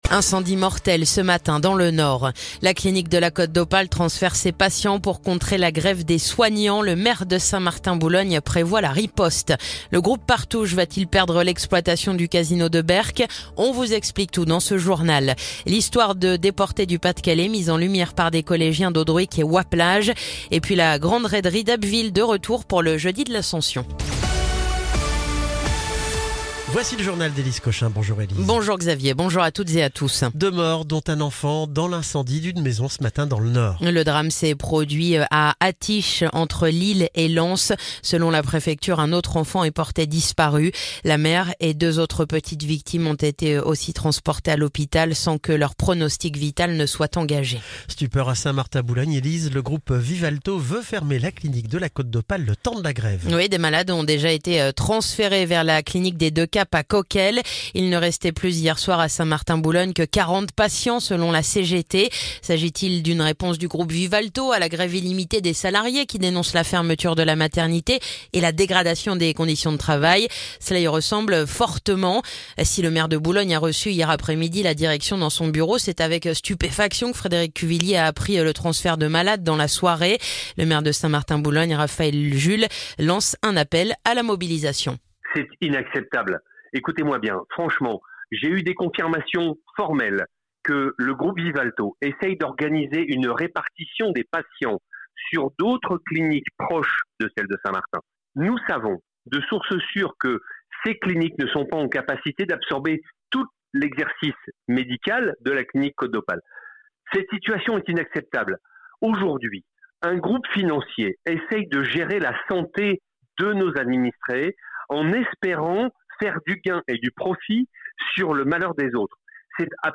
Le journal du mardi 27 mai